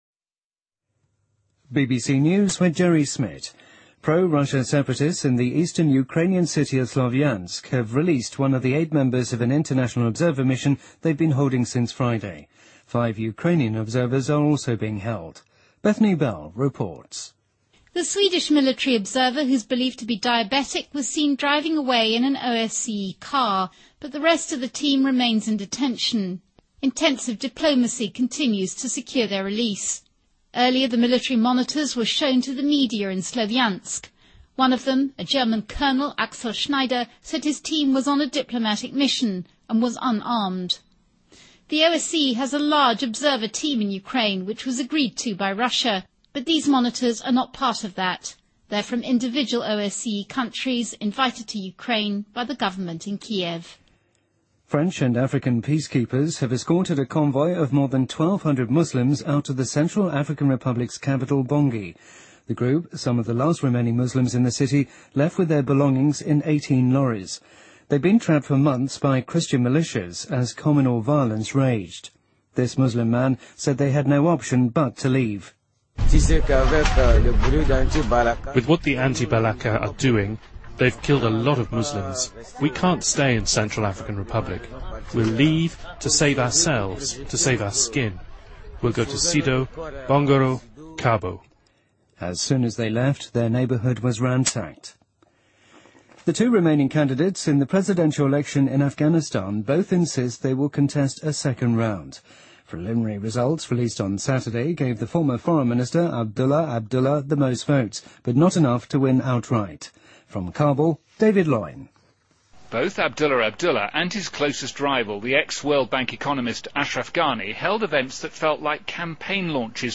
BBC news,圣方济教皇宣布两位前教皇约翰23世和约翰·保罗二世为圣者